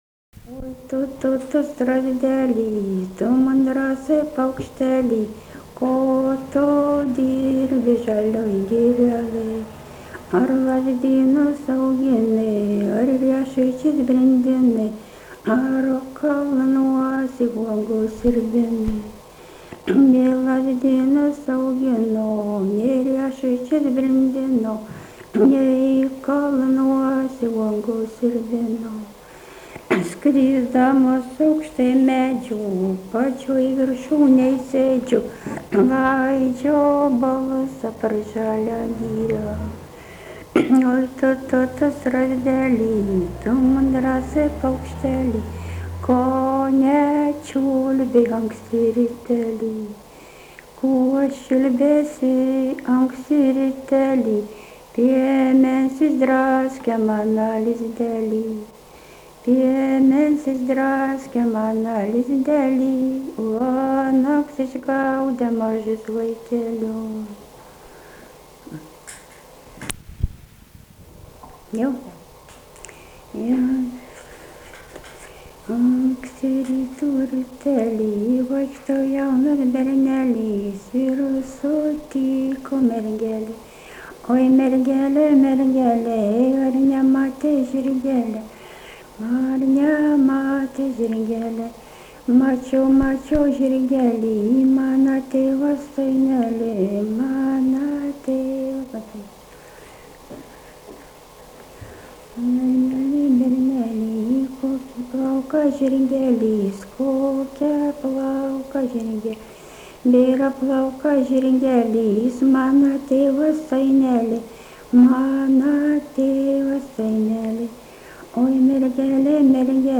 daina
Vabalninkas
vokalinis